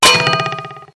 Free Samples : samples de effects .Efectos especiales,sonidos extrańos..